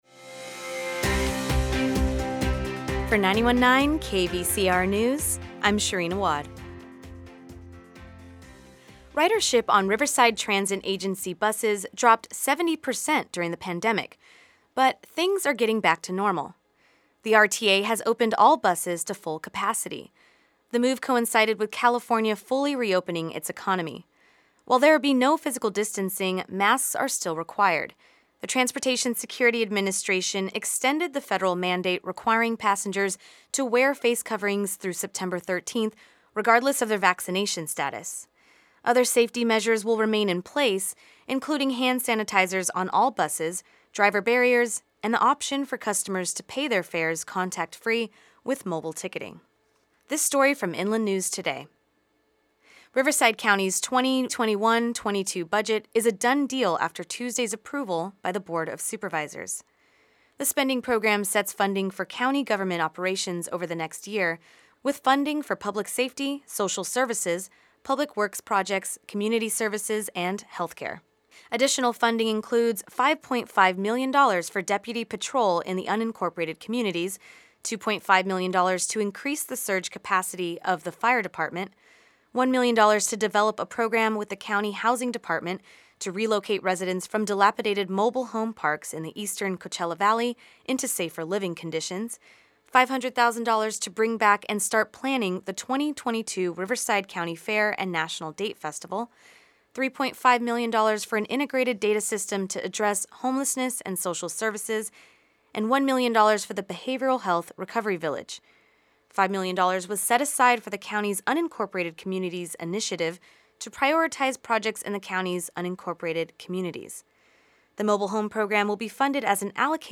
The Midday News Report
KVCR News has your daily news rundown at lunchtime.